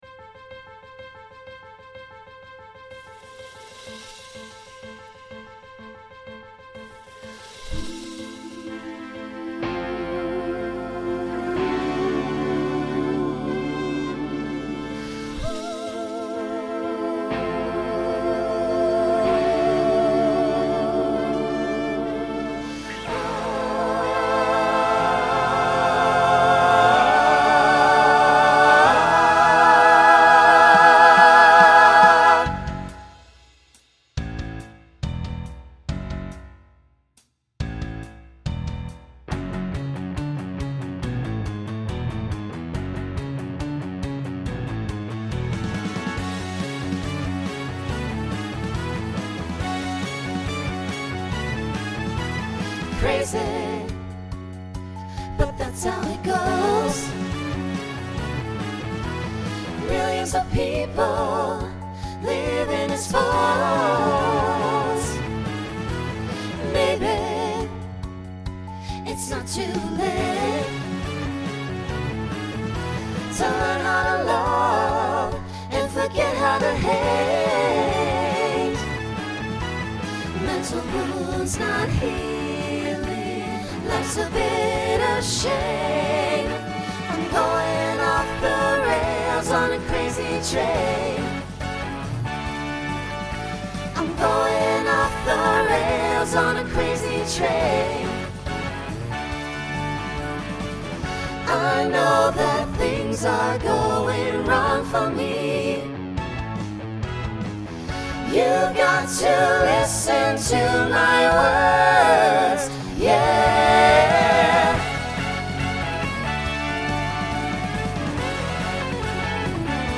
Rock
Voicing SATB